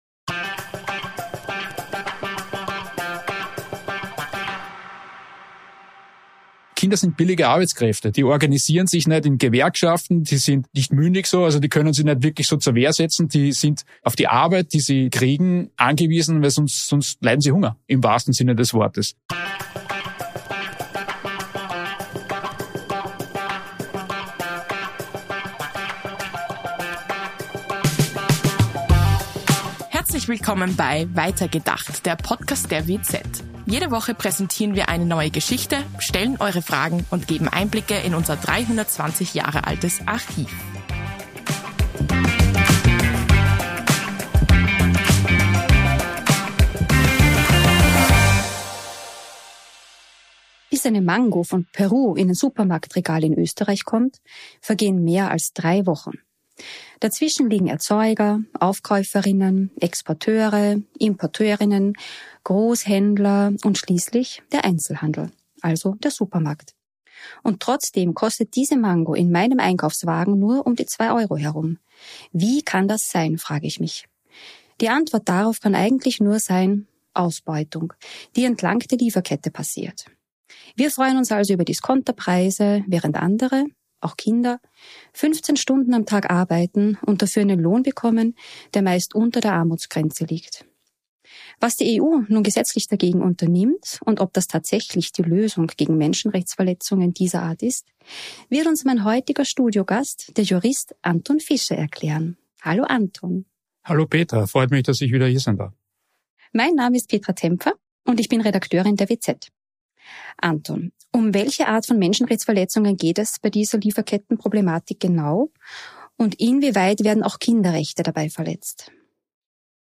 Studiogast und Jurist